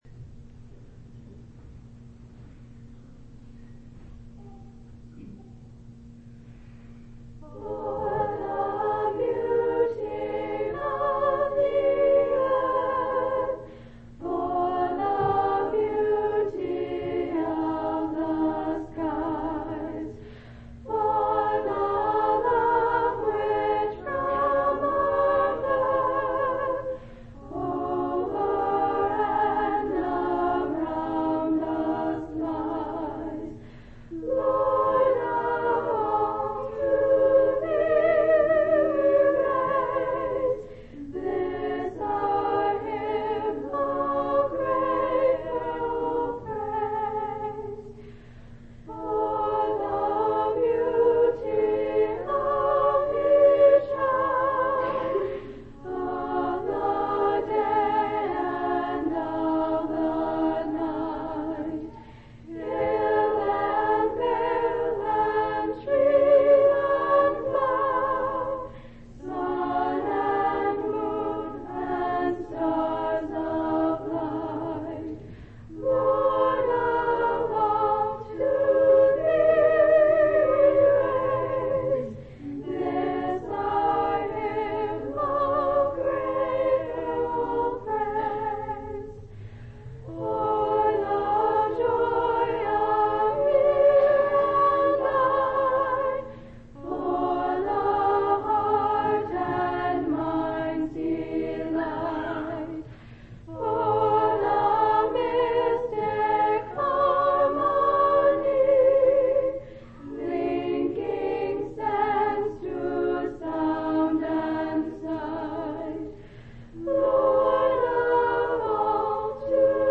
5/9/2004 Location: Temple Lot Local Event